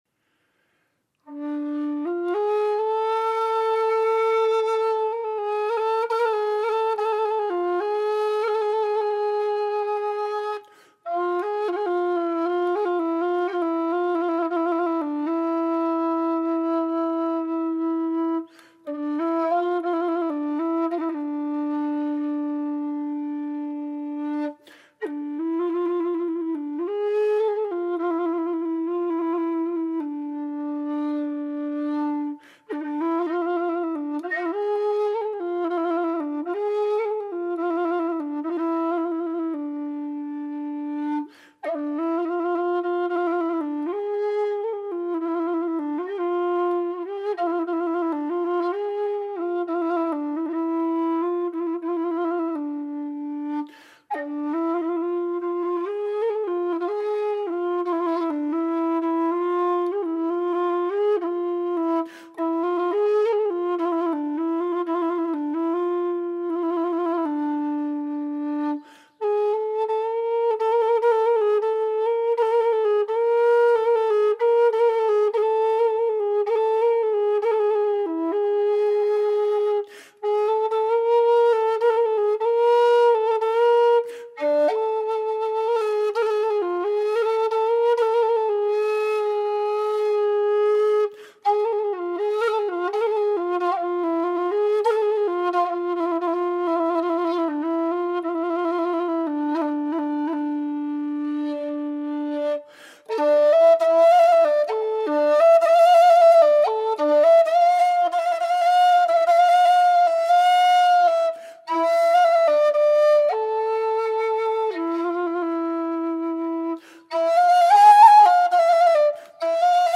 Wide-bore low D Kaval whistle
made out of thin-walled aluminium tubing with 22mm bore
The low D Kaval whistle plays these scale notes in the low octave: D E F G# A Bb
Low-D-WB-Kaval-impro.mp3